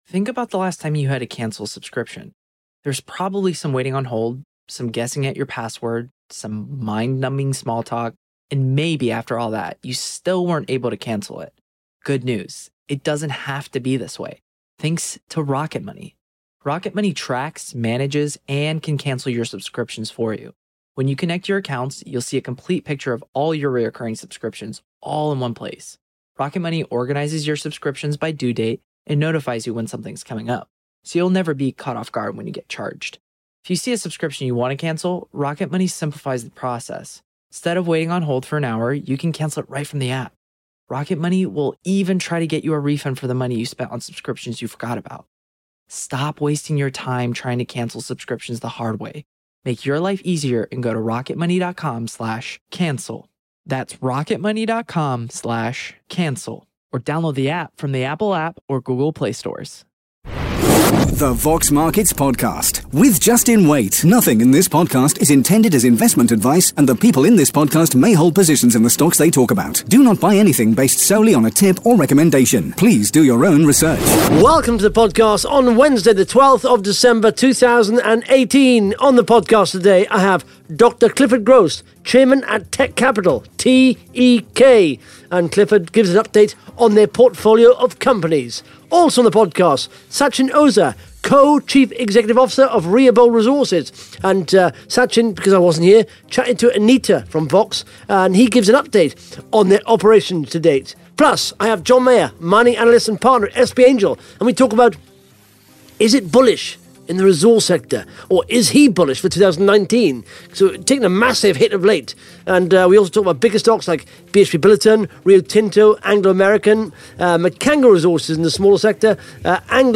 (Interview starts at 16 minutes 40 seconds)